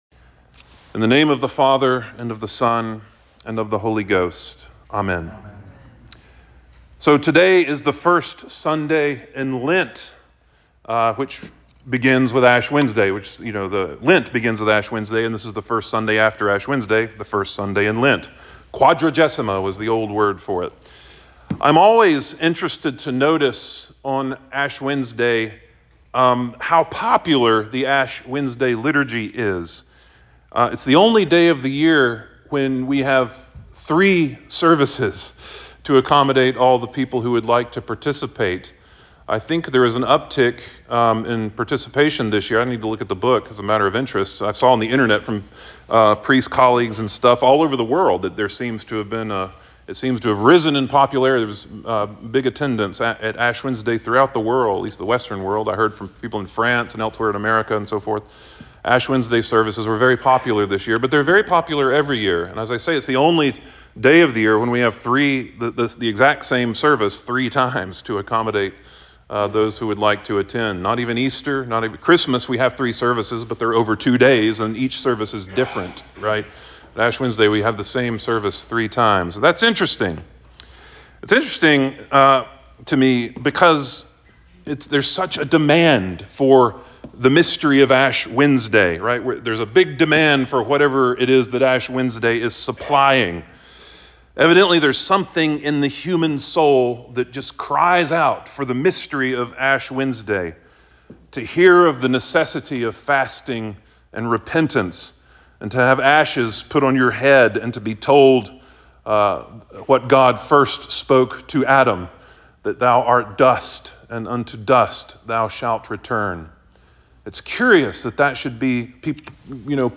Sermon for the First Sunday in Lent 03.09.2025